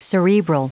Prononciation du mot cerebral en anglais (fichier audio)